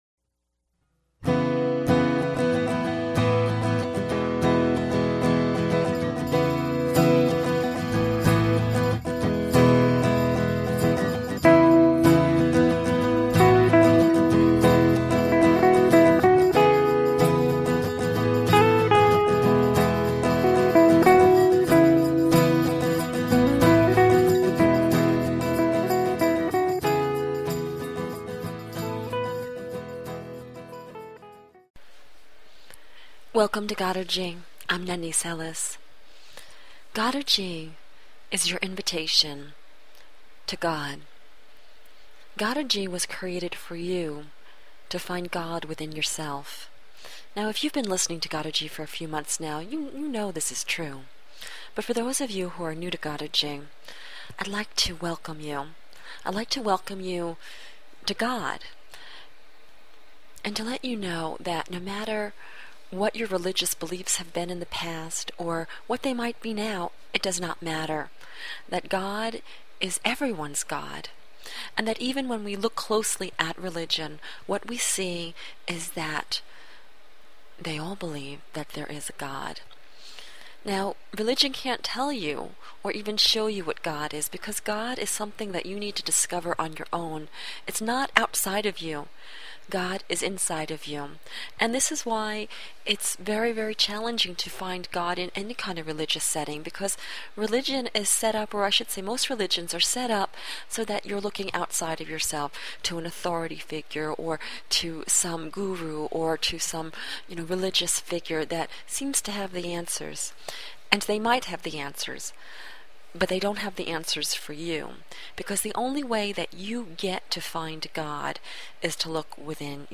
Talk Show Episode, Audio Podcast, Godergy and Courtesy of BBS Radio on , show guests , about , categorized as